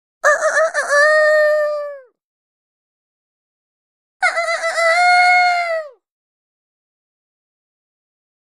Rooster Crows - 2 Effects; Classic Rooster Call.